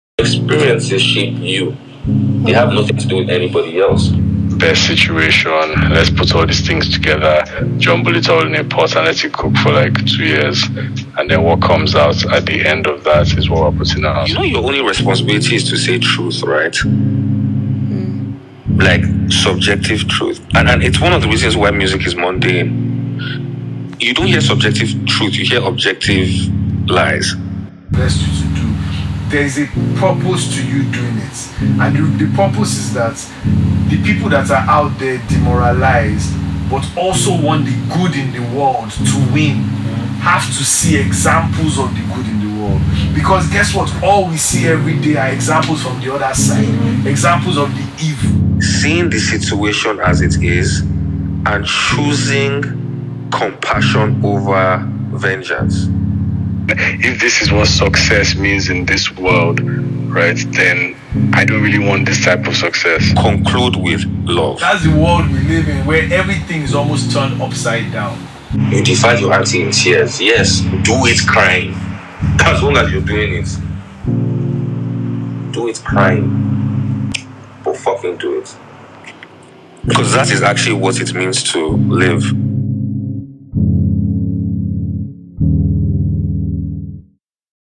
Genre: Afrobeats
Nigerian / African Music